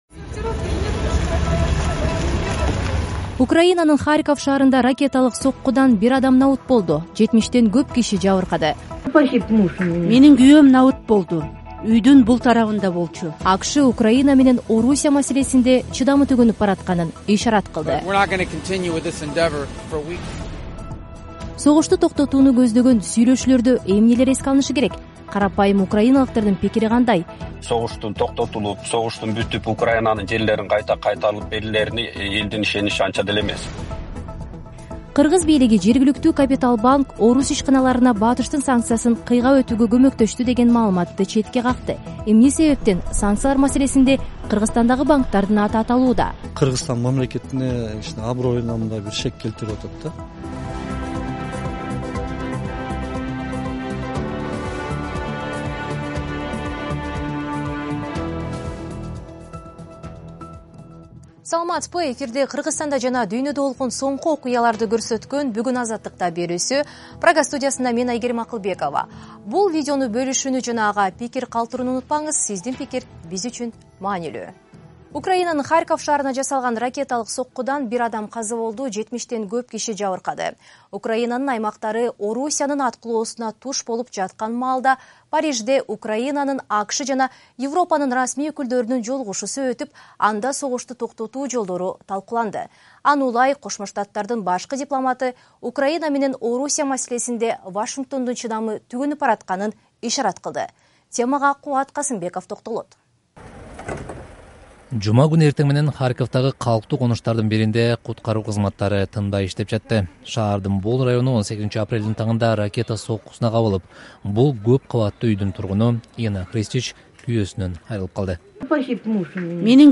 Жаңылыктар | 18.04.2025 | Украинанын келечеги: Киевдеги кыргыздар эмне дейт?
Киевдеги кыргызстандык менен маектешебиз.